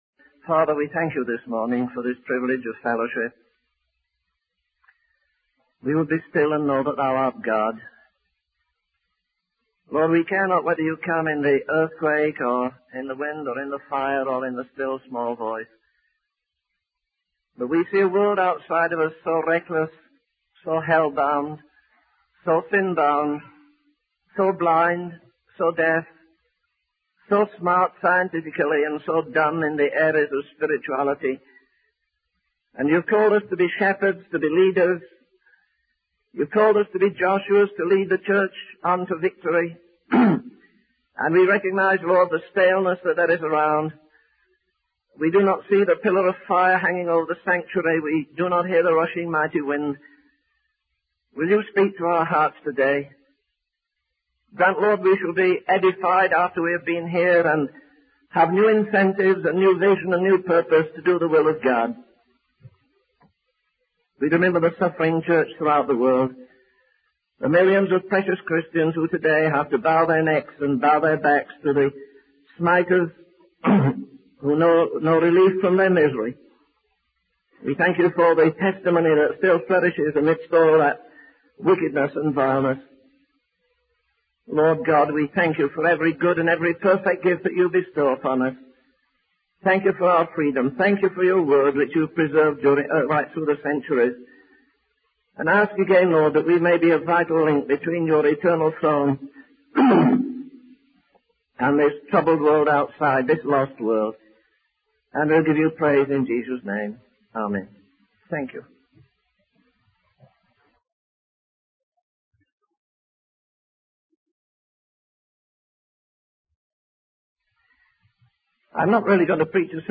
In this sermon, the speaker contrasts the superficiality of modern Christian gatherings with the deep devotion and sacrifice of believers in the past.